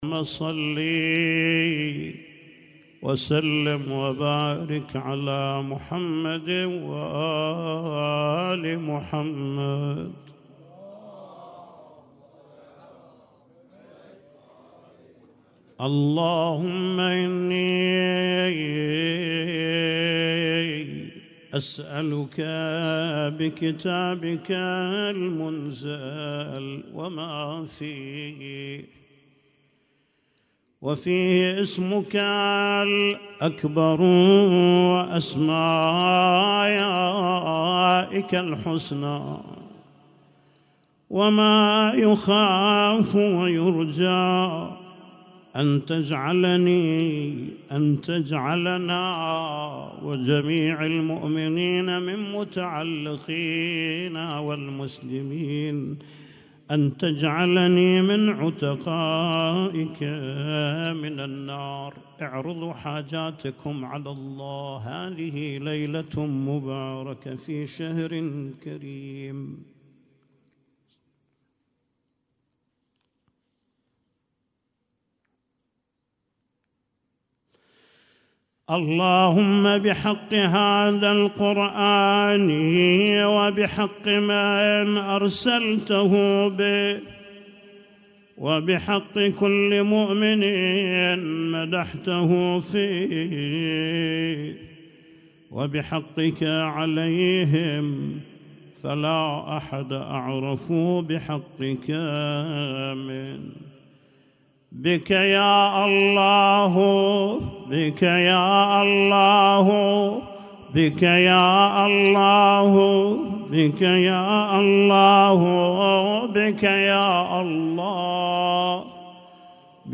Husainyt Alnoor Rumaithiya Kuwait
اسم التصنيف: المـكتبة الصــوتيه >> الادعية >> ادعية ليالي القدر